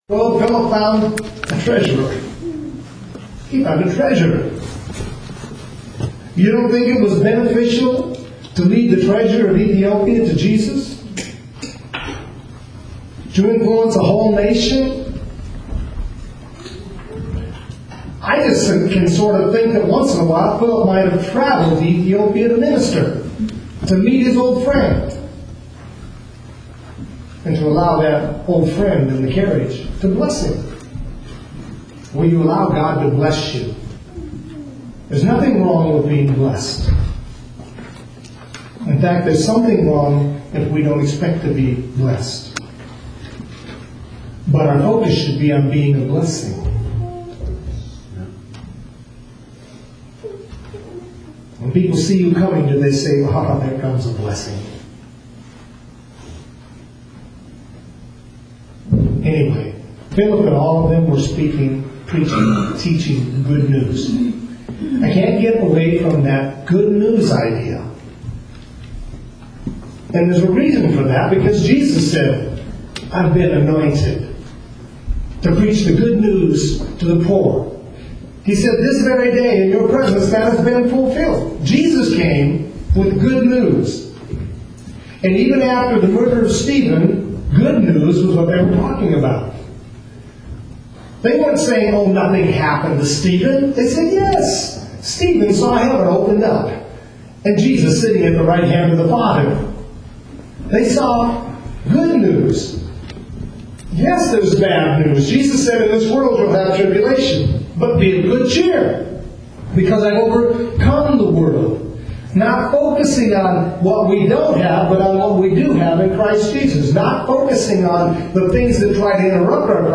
Longer sermons are broken up into smaller...
PREACHING GOOD NEWS 2.WMA